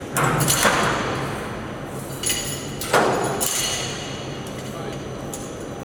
碰撞.WAV
每一次碰撞声